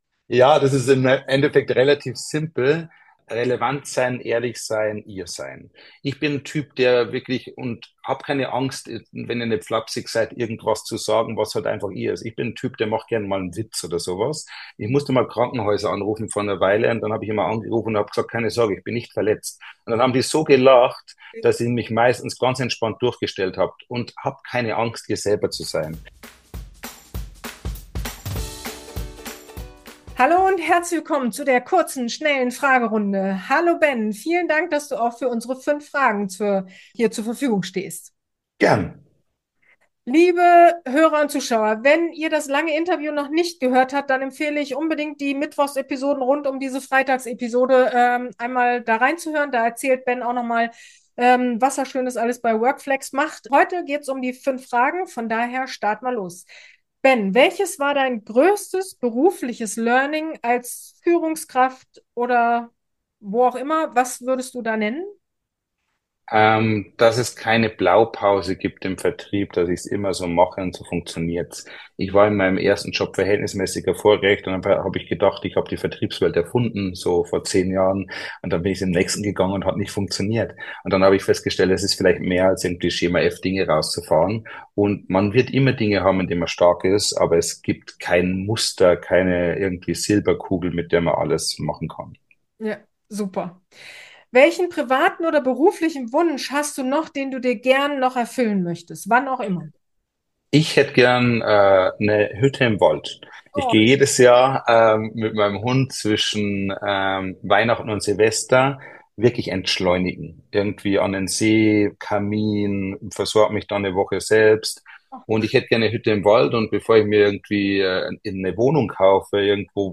In dieser Freitags-Episode erwartet Dich eine schnelle Fragerunde